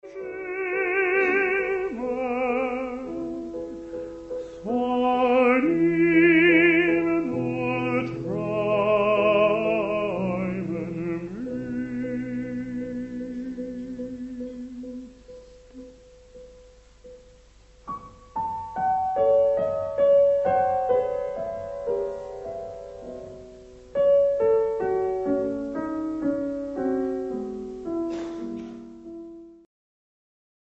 He frequently composed for and performed with for his life partner and muse, Peter Pears, himself a superb if somewhat idiosyncratic tenor.
It was recorded live as part of an Aldeburgh Festival recital given in the Jubilee Hall on 15 June, 1958. The heavens stoop down to kiss the earth in a mystical nocturnal scene full of the romanticism and atmosphere we associate with the lieder tradition.
Excerpt of Britten/Pears performance of Mondnacht